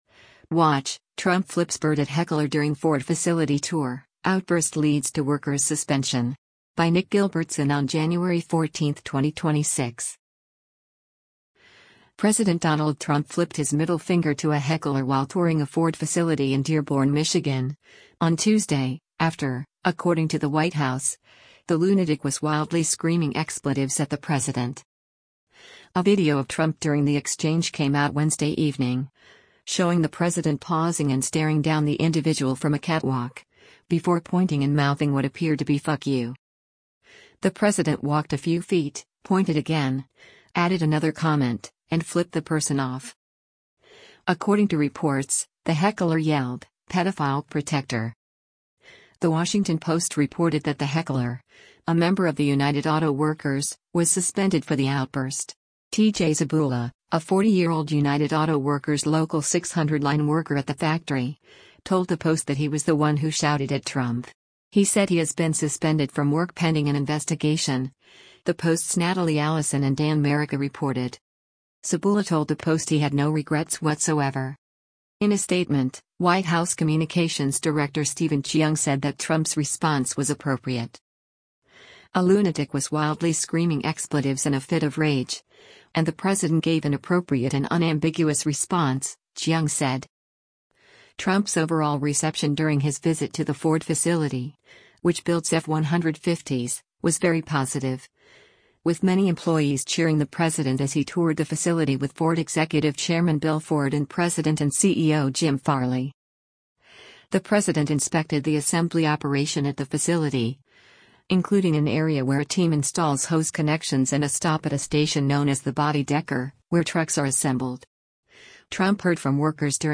US President Donald Trump speaks as he tours Ford Motor Company's River Rouge complex in D
Trump’s overall reception during his visit to the Ford facility, which builds F-150s, was very positive, with many employees cheering the president as he toured the facility with Ford Executive Chairman Bill Ford and President and CEO Jim Farley.